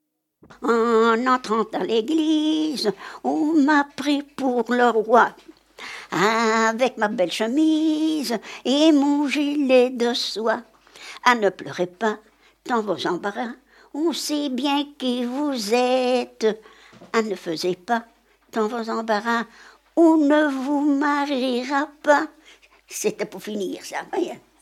Genre : chant
Type : chanson narrative ou de divertissement
Lieu d'enregistrement : Vierves-sur-Viroin
Support : bande magnétique